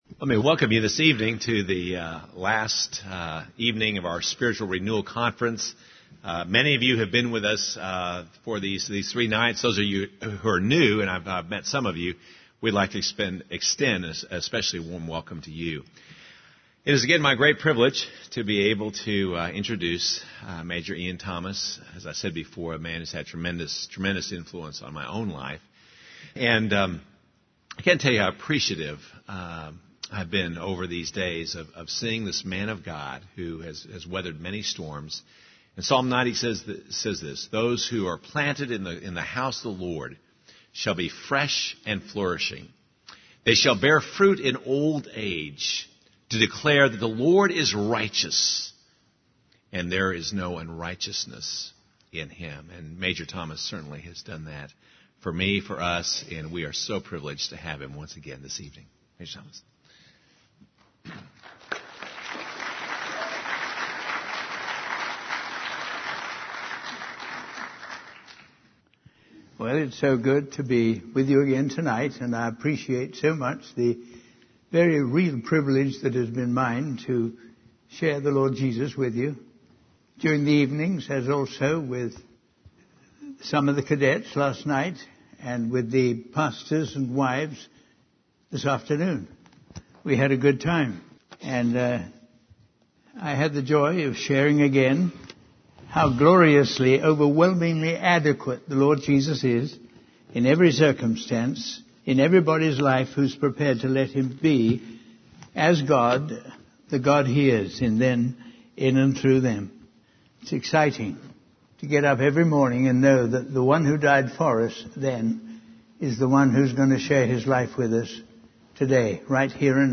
In this sermon, the preacher emphasizes the importance of following God's instructions and being in the right place at the right time. He uses the story of Jesus feeding the five thousand with five loaves and two fishes as an example of how God can work miracles through simple obedience.